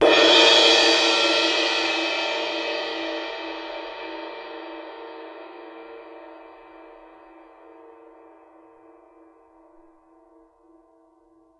susCymb1-hit_f_rr1.wav